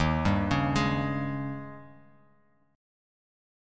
D#11 Chord
Listen to D#11 strummed